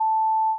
Free UI/UX sound effect: Notification Ding.
Notification Ding
026_notification_ding.mp3